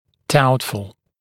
[‘dautfl][‘даутфл]сомнительный, вызывающий сомнения